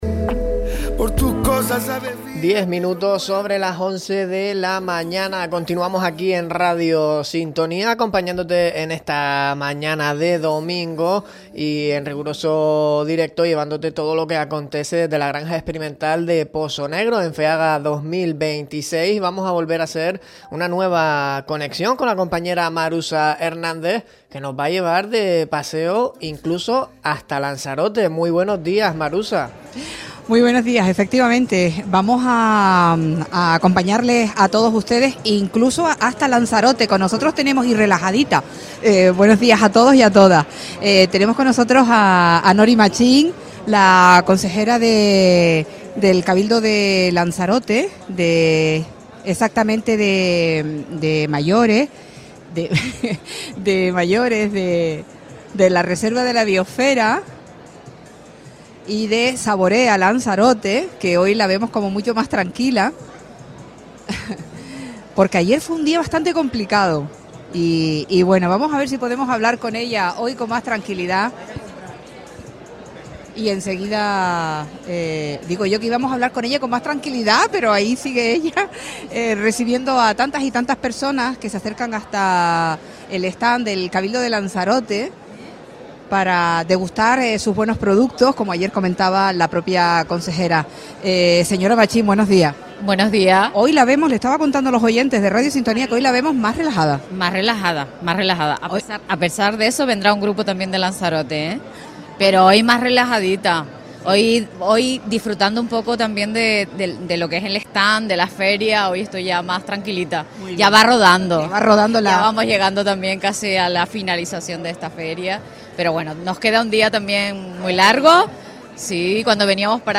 Entrevistas
La consejera de Promoción Económica, Saborea Lanzarote y Mayores del Cabildo de Lanzarote, Nori Machín, atiende los micrófonos de Radio Sintonía desde el stand de la isla conejera